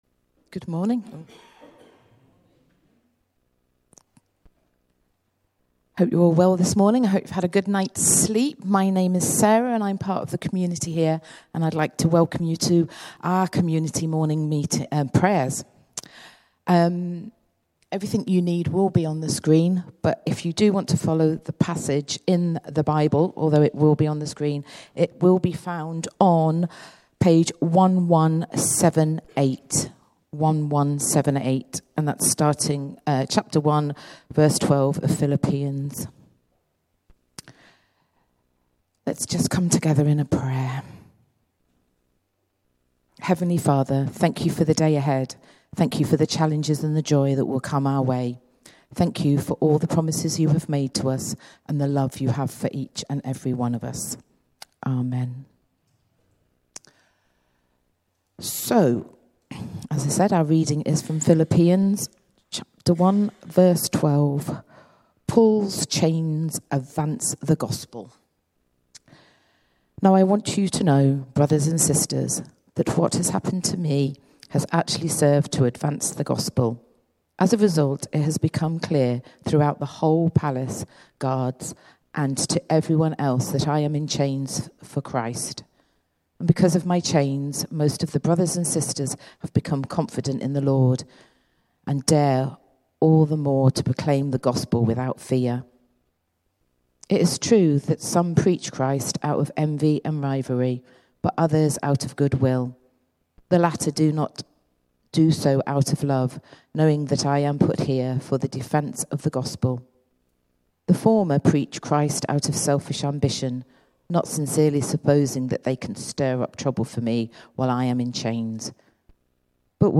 Morning Prayer